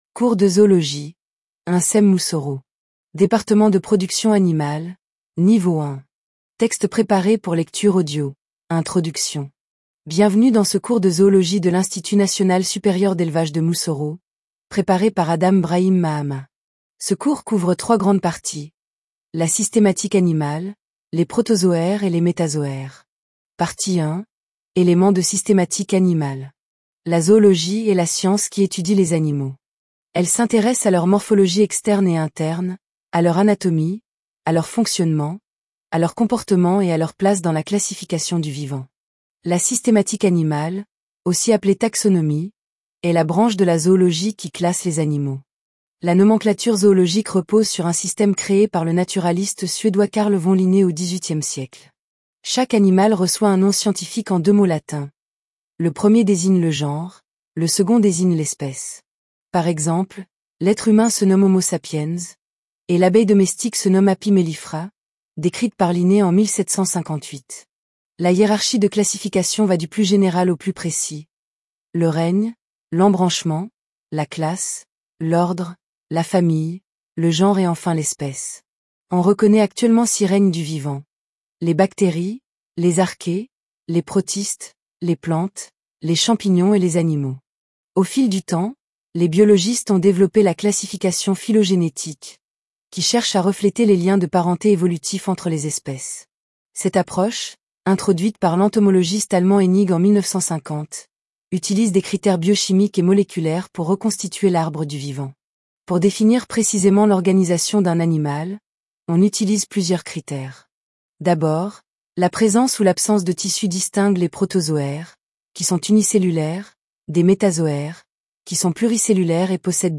ZOOLOGIE - Podcast MP3 - cours